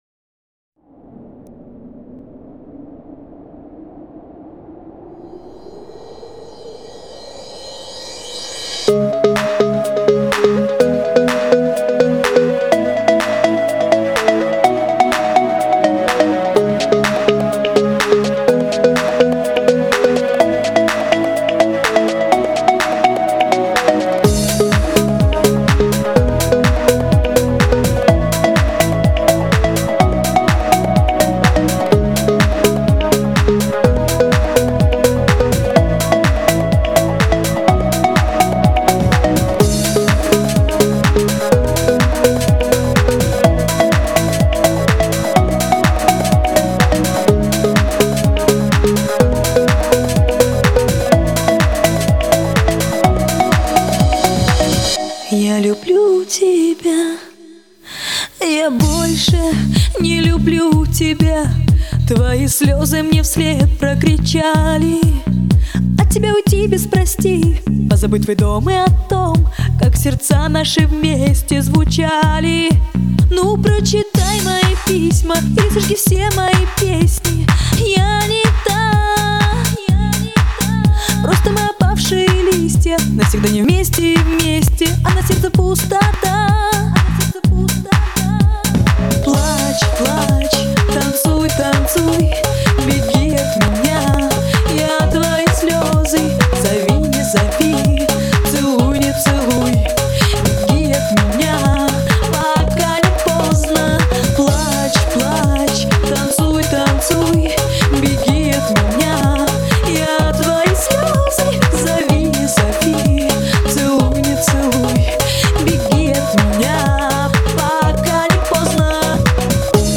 Ретро музыка